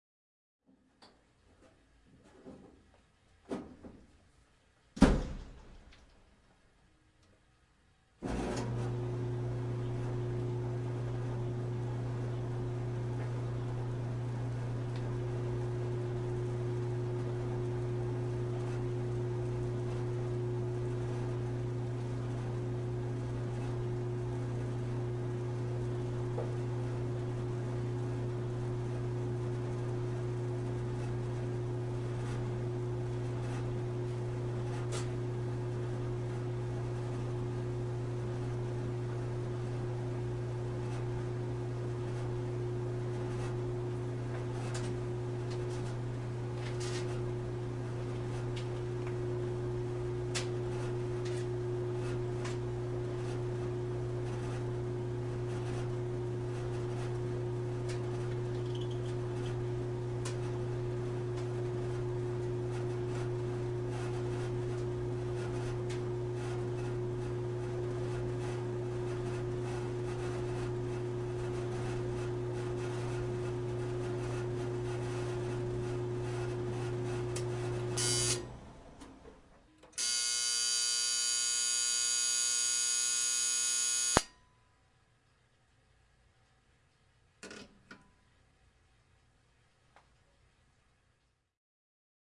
旧衣服烘干机运行
描述：旧衣服烘干机运行